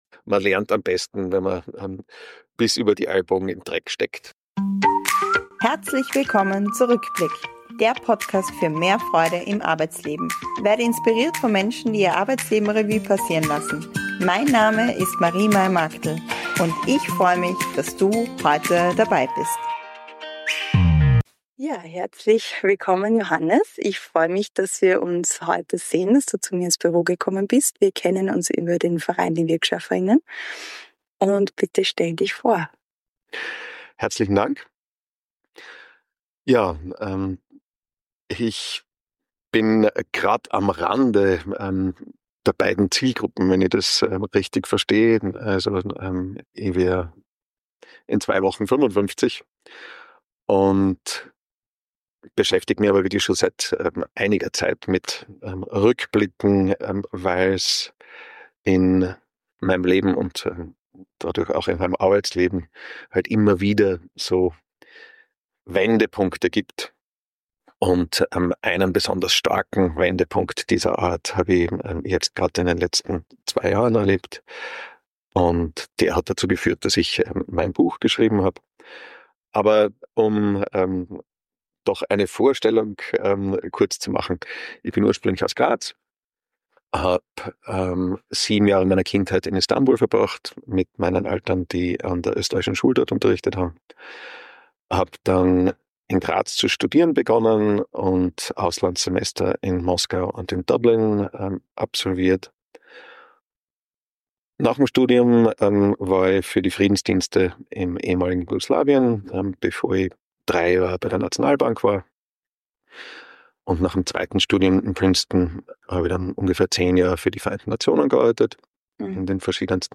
Wir sprechen über Selbstüberforderung, über den Körper, der Grenzen aufzeigt, und über die Kraft der Reflexion, wenn alles zu viel wird. Ein Gespräch über Werte, Wandel und die Frage: Wie lernt man Grenzen zu setzen?